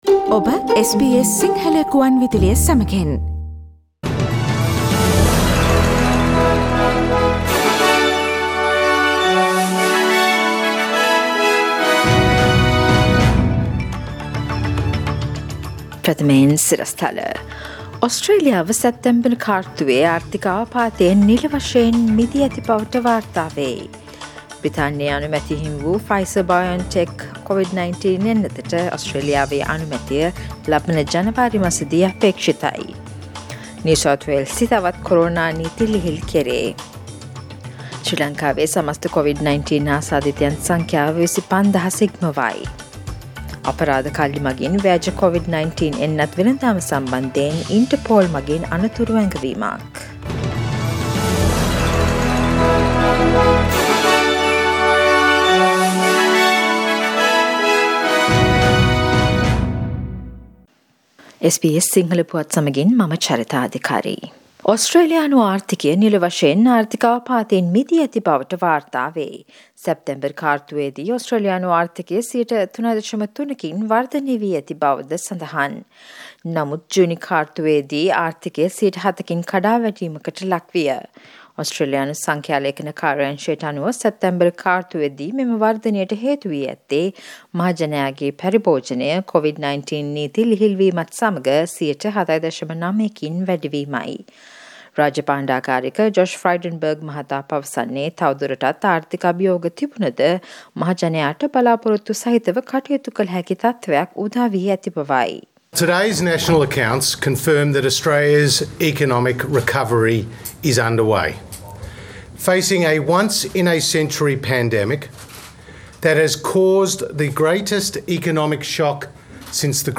SBS සිංහල සේවයේ අද දවසේ ප්‍රවෘත්ති ප්‍රකාශය: දෙසැම්බර් මස 3 වන බ්‍රහස්පතින්දා